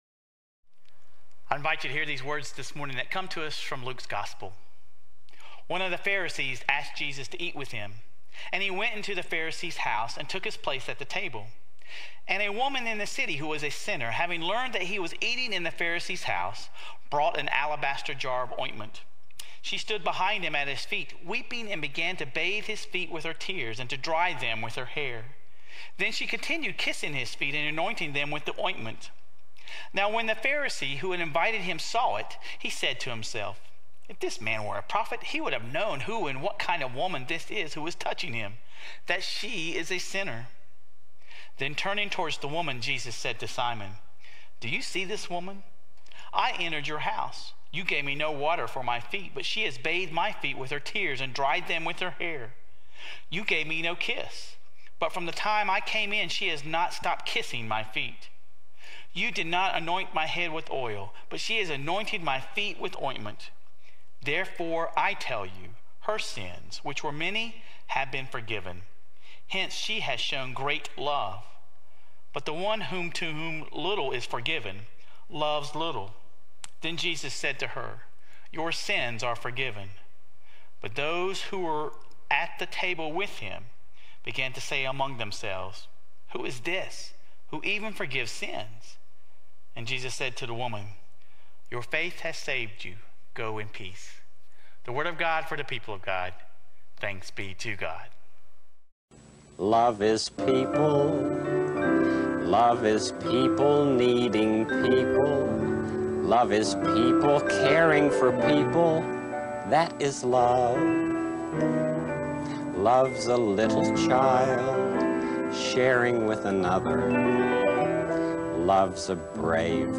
Sermon Reflections: How does the story of the sinful woman and the Pharisee challenge our understanding of hospitality and inclusion?